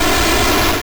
Monster4.wav